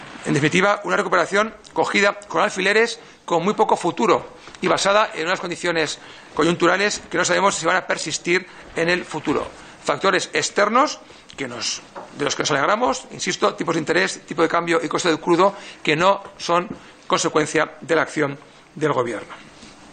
Fragmento de la intervención de Juan Moscoso del Prado en la Comisión de Economía tras la comparecencia del ministro Luis de Guindos para informar sobre la evolución de la economía española 5/05/2015